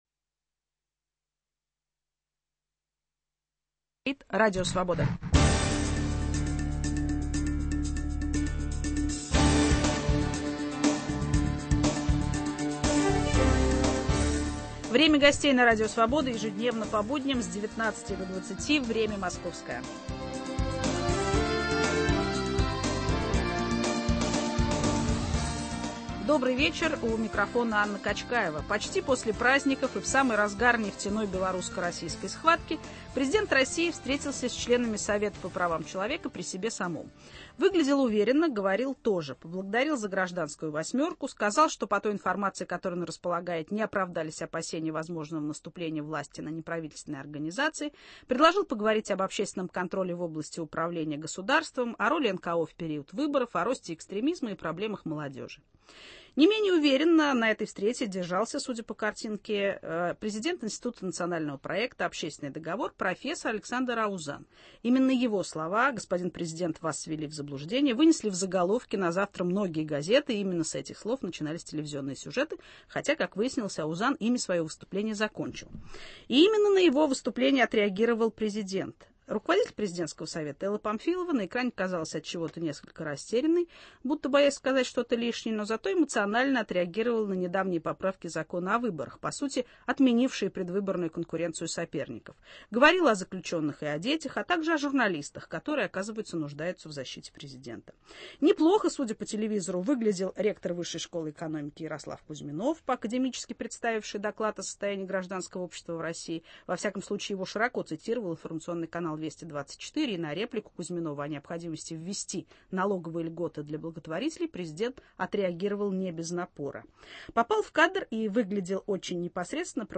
Послесловие к встрече президента с представителями гражданского общества – так звучит тема. Гость студии – Александр Аузан, президент Института национального проекта «Общественный договор» и профессор кафедры институциональной экономики Московского государственного университета.